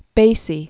(bāsē), William Known as "Count Basie." 1904-1984.